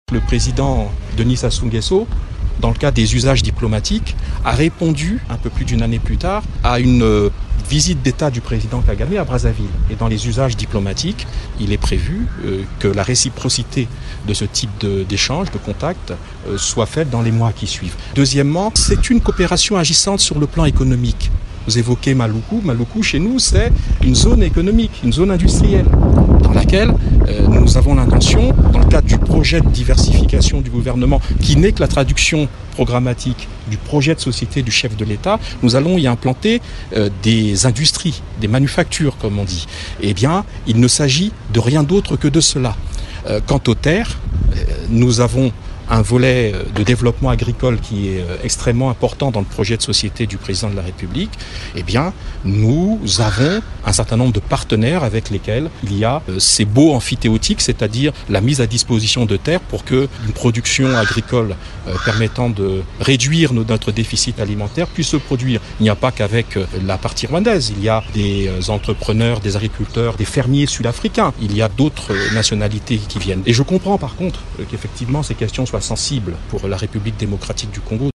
Le ministre du Congo Brazzaville de la communication répondait ainsi à une question de la presse kinoise au sortir d’une audience que le Premier ministre Jean Michel Sama Lukonde a accordée à son homologue du Congo Brazzavill, Anatole-Collinet Makoso.